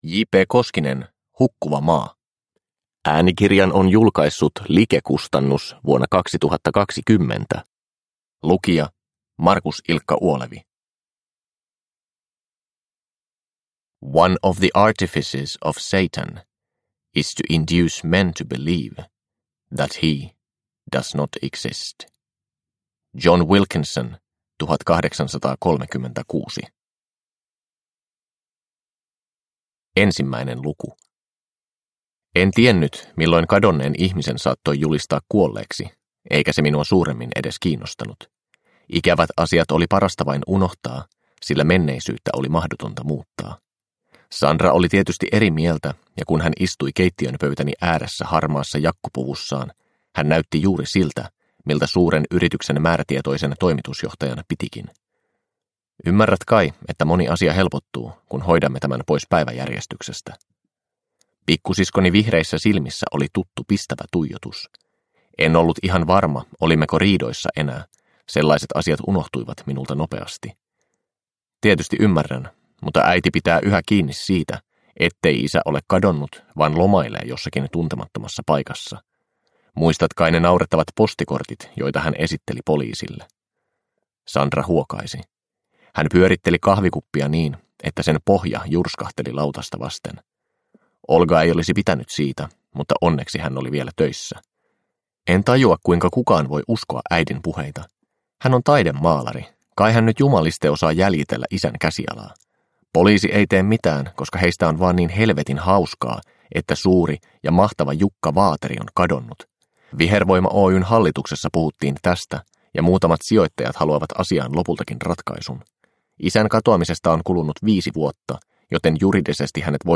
Hukkuva maa – Ljudbok – Laddas ner